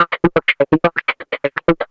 Index of /90_sSampleCDs/Houseworx/12 Vocals/74 Processed Vocal Loops